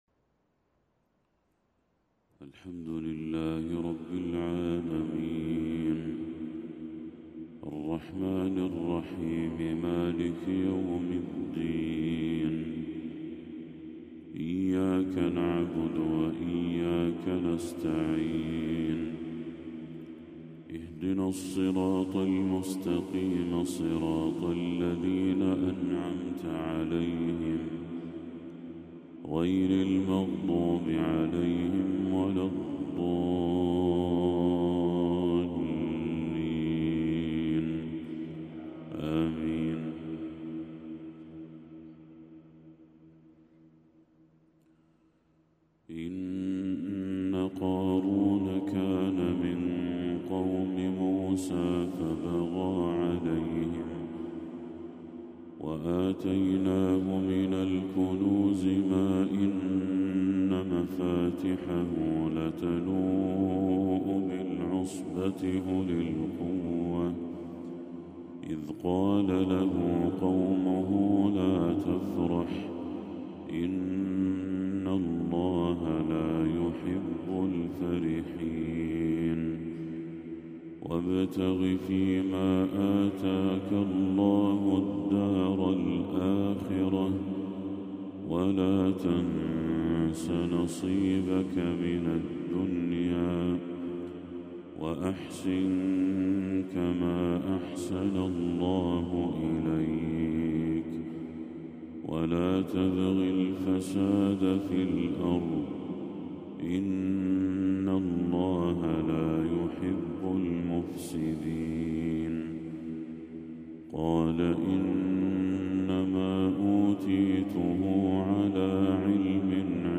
تلاوة مهيبة لخواتيم سورة القصص
فجر 21 ربيع الأول 1446هـ